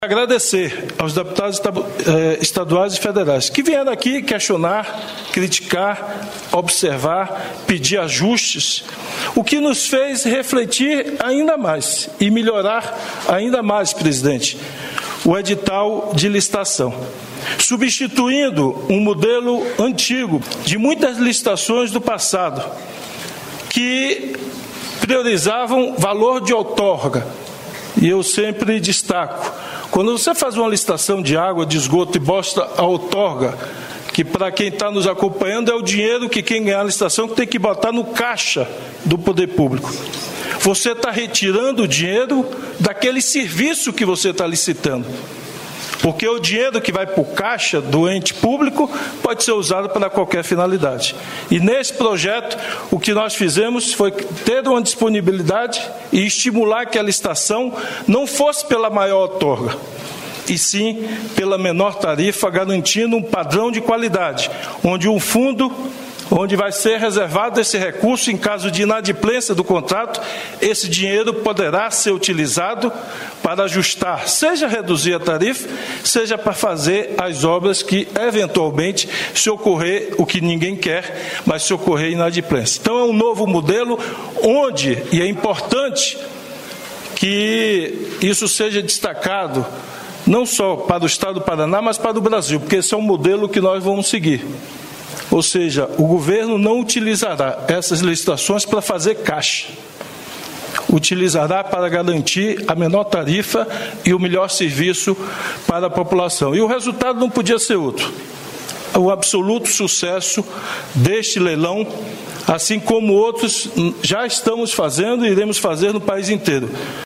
Sonora do ministro-chefe da Casa Civil, Rui Costa, sobre os investimentos nas rodovias do Estado